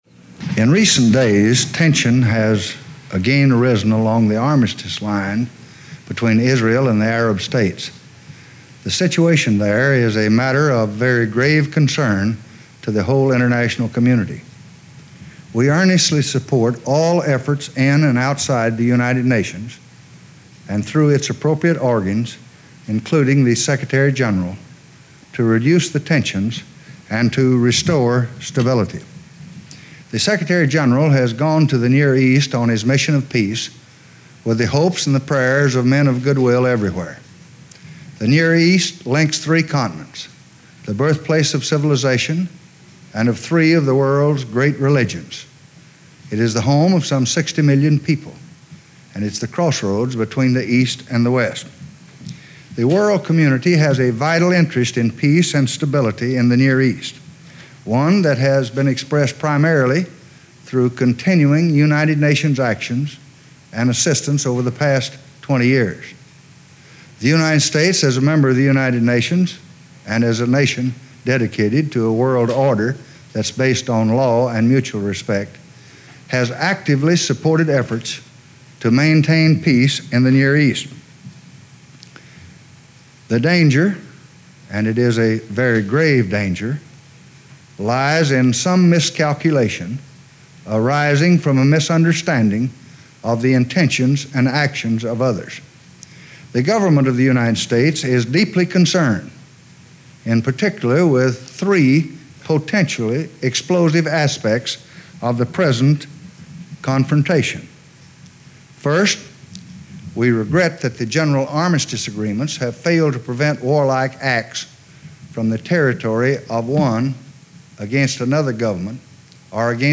Statement on Rising Tensions in the Middle East
delivered 23 May 1967, White House, Washington, D.C.
Audio Note: AR-XE = American Rhetoric Extreme Enhancement